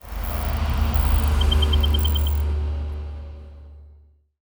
UI Whoosh Notification 3.wav